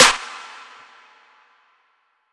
atysClap.wav